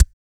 DRIBBLE3.wav